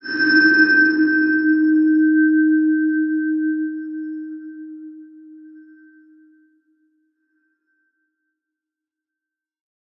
X_BasicBells-D#2-mf.wav